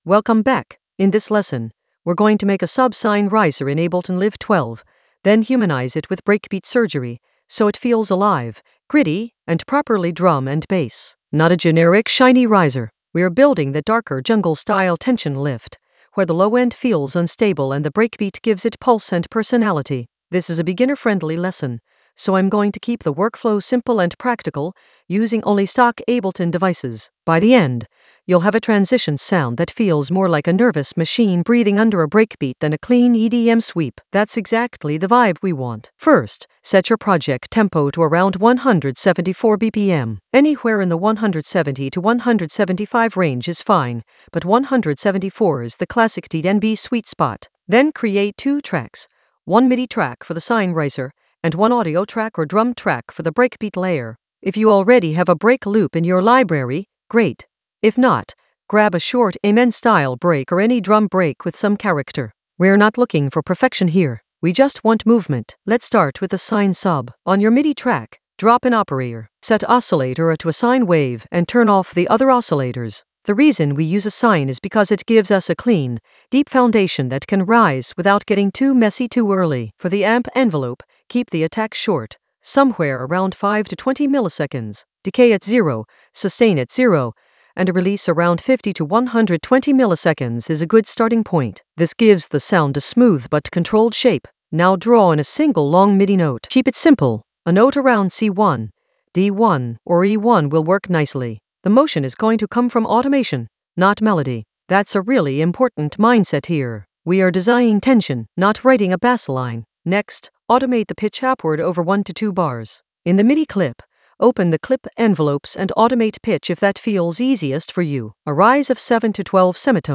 Narrated lesson audio
The voice track includes the tutorial plus extra teacher commentary.
An AI-generated beginner Ableton lesson focused on Subsine in Ableton Live 12: humanize it with breakbeat surgery in the Risers area of drum and bass production.